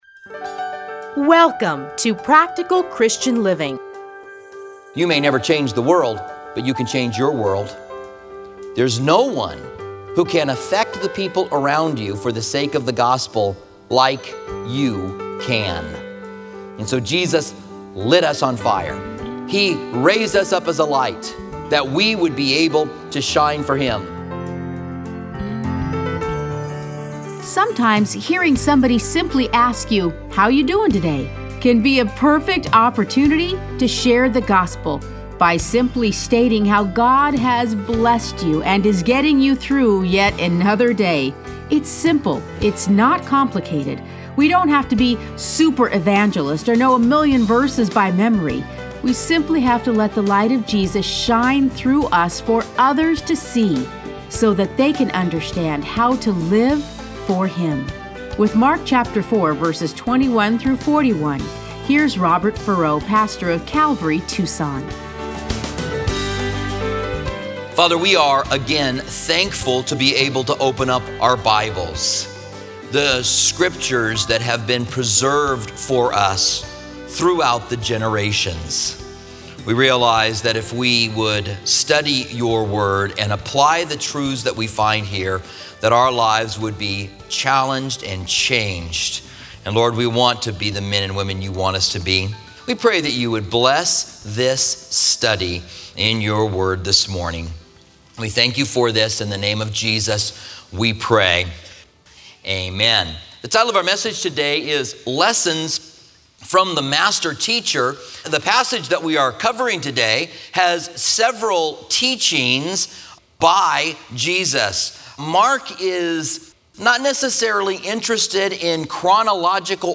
Listen to a teaching from Mark 4:21-40.